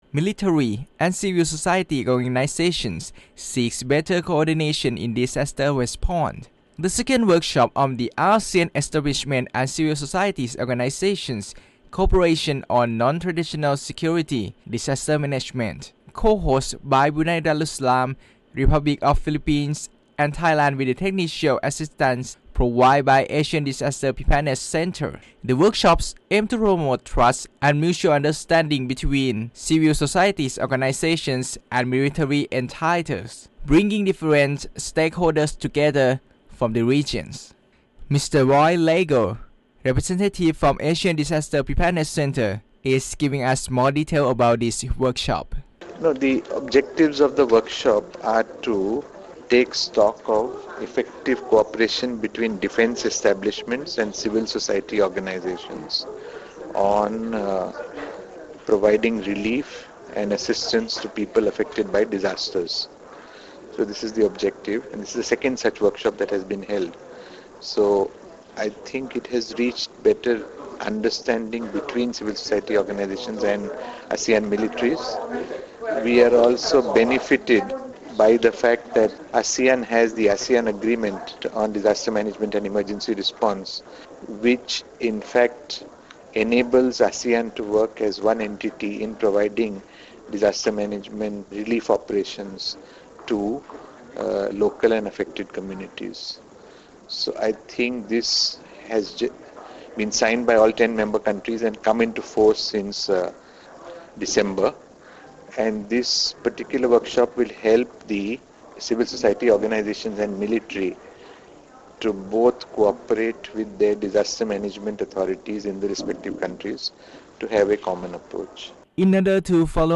This recorded interview has been aired through FM. 88
Interview_Radio Thailand_5July.mp3.mp3